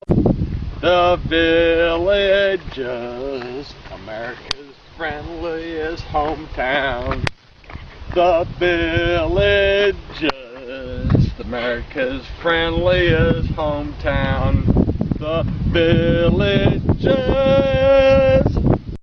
Category: Radio   Right: Both Personal and Commercial